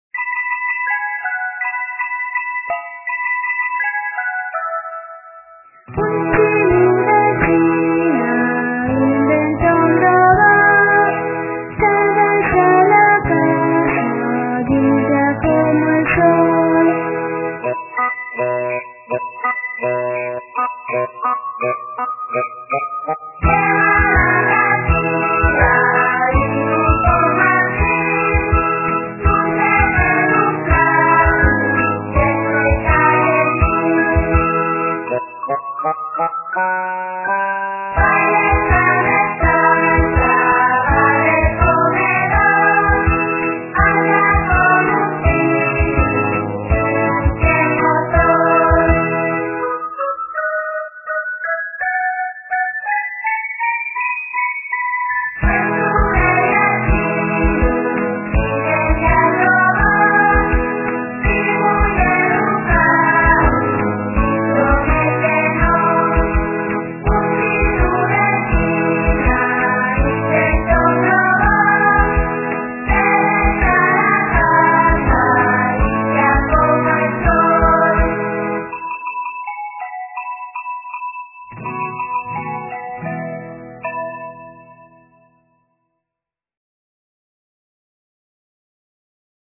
Son toos escolinos del C.P de Lieres. (Siero) La música
guitarres